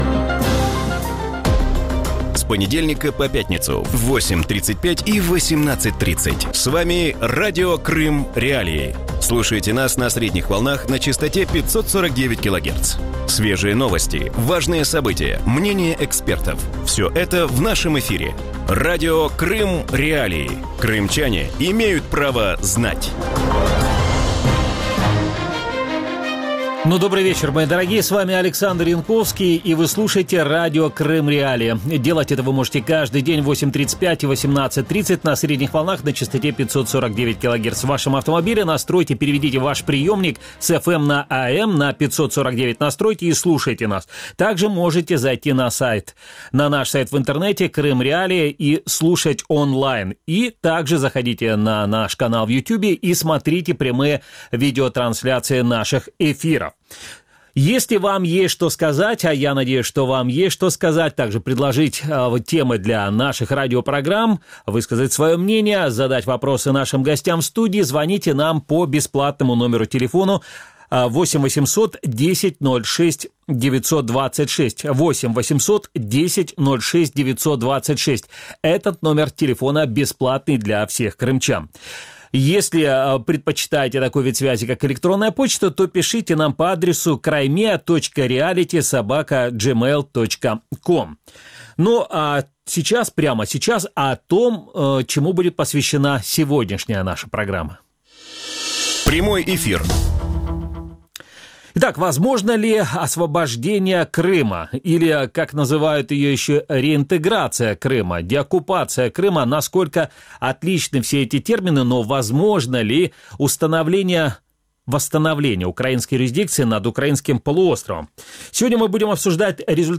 В вечернем эфире Радио Крым.Реалии обсуждают отношение украинцев к Крыму и возможность реинтеграции полуострова. Что думают о Крыме в Украине, каким будет политическое будущее полуострова и есть ли возможность вернуть его под контроль Украины?